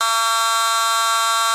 Fire Alarm Sound Button: Meme Soundboard Unblocked
Fire Alarm